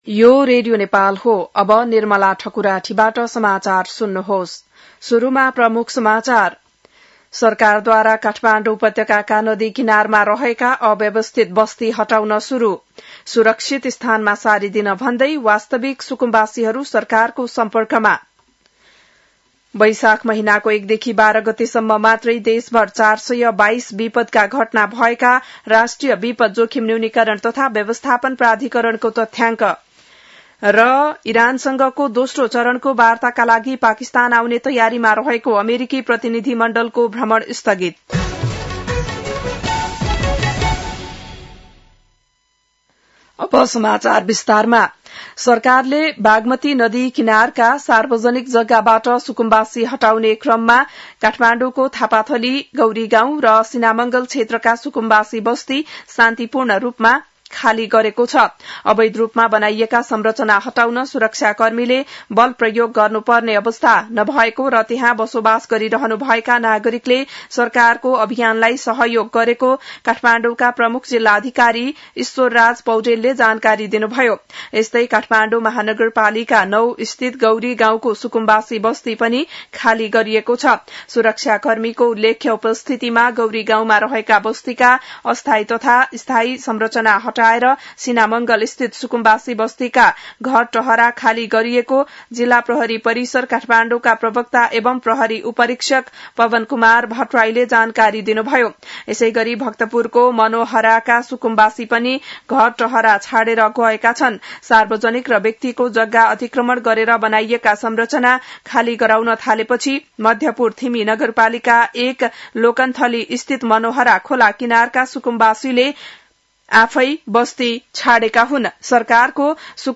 बिहान ९ बजेको नेपाली समाचार : १३ वैशाख , २०८३